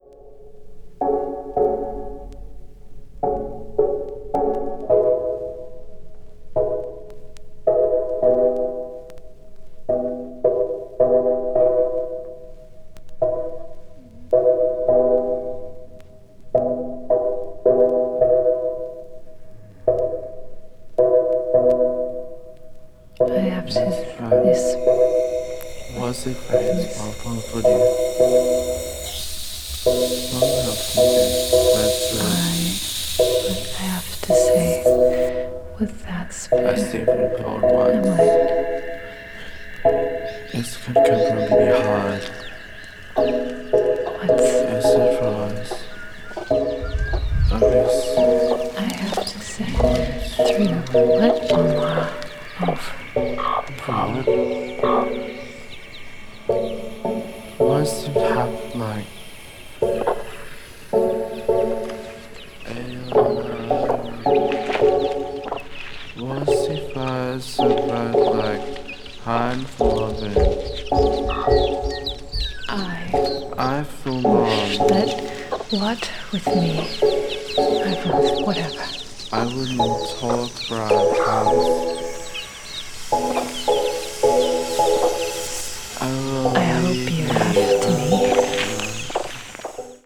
ambient   collage   drone   experimental   modern classical